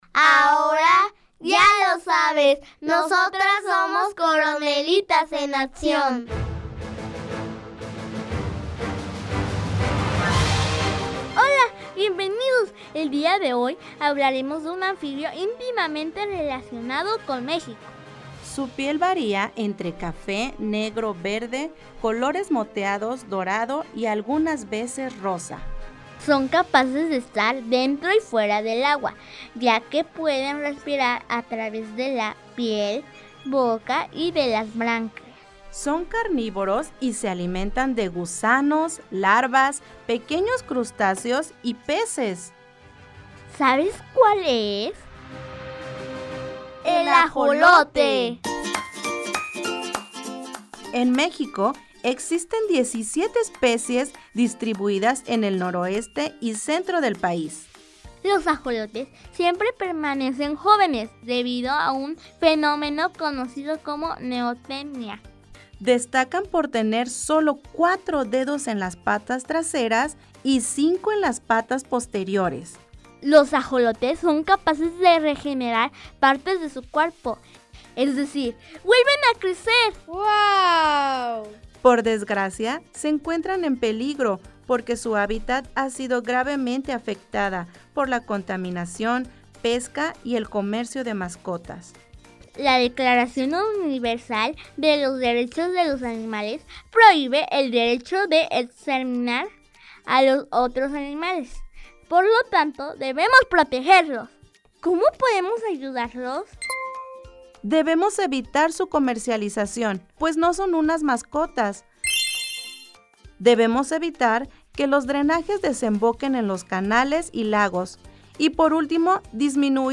Son cápsulas radiofónicas que se encargan de hacer reflexionar acerca de los derechos de los animales, enfocándose en el conocimiento de especies en peligro de extinción o vulnerables, y haciendo referencia a la Declaración Universal de los Derechos de los Animales.